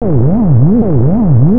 cloud sound.
cloud.wav